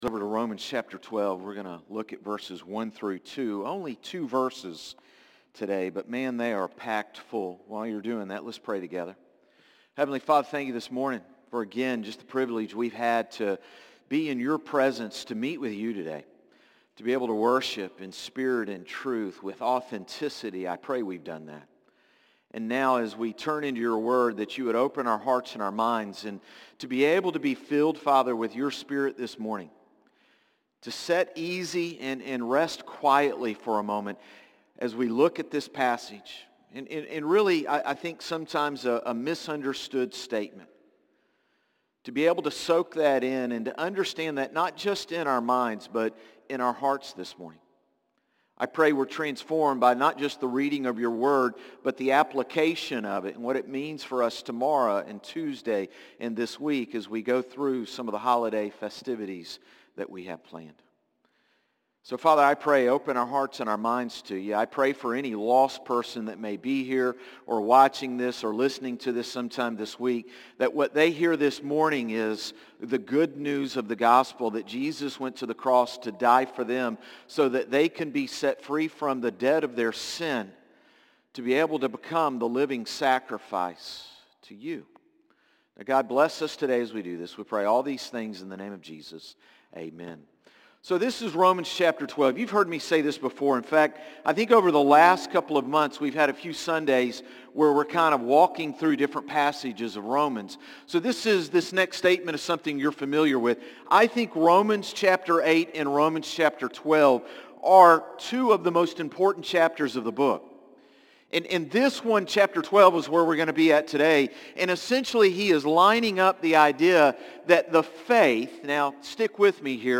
Sermons - Concord Baptist Church